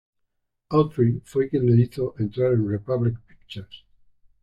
Pronounced as (IPA) /ˈkjen/